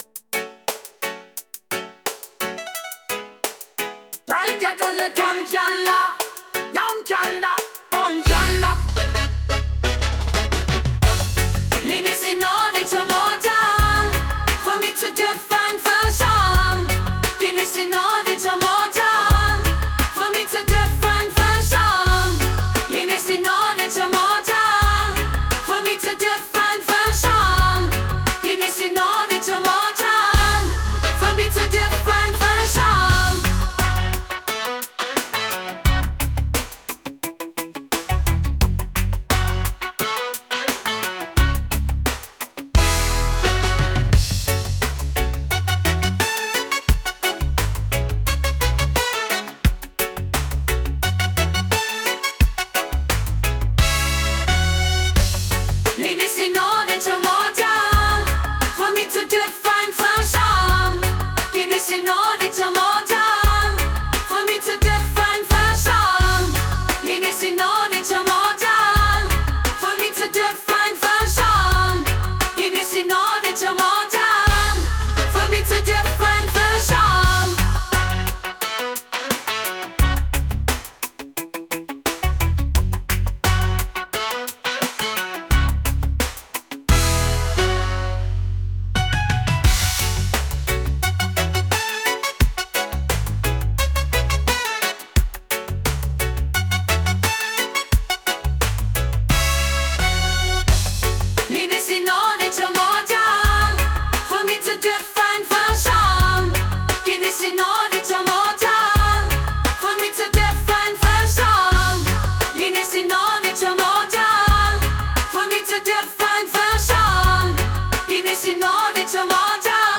reggae | hip hop | electronic